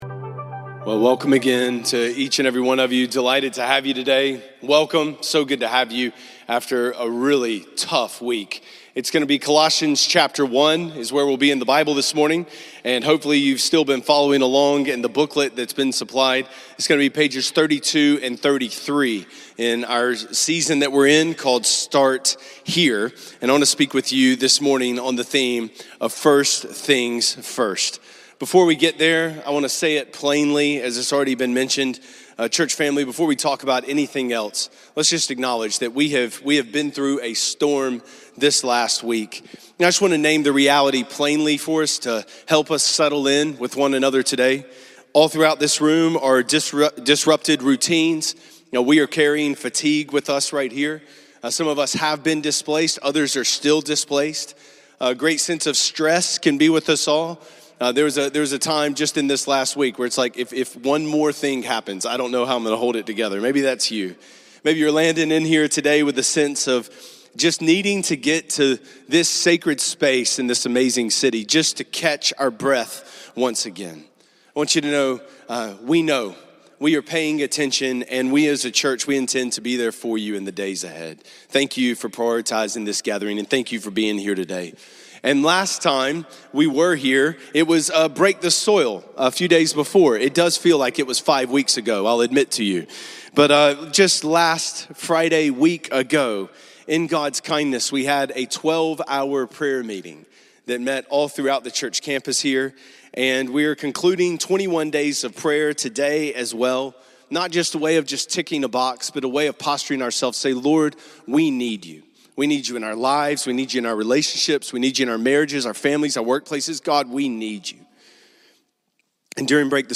sermon is from Colossians 1:15–20 and the theme is “First Things First.”